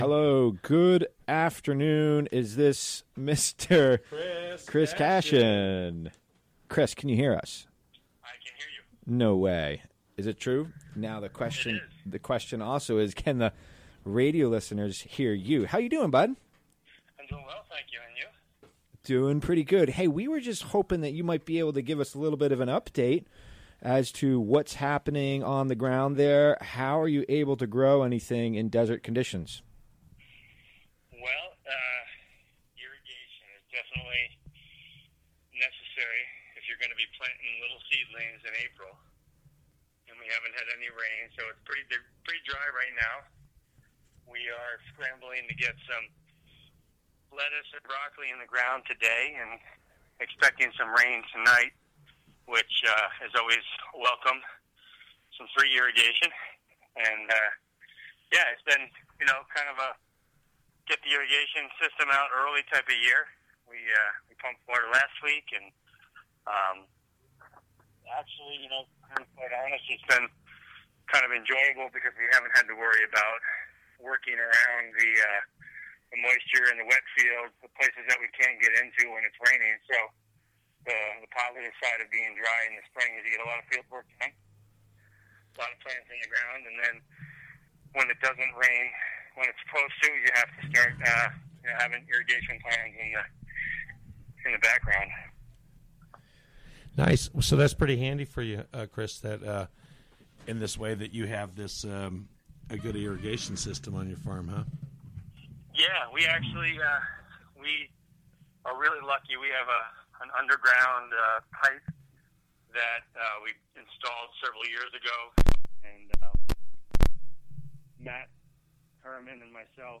Recorded during the WGXC Afternoon Show.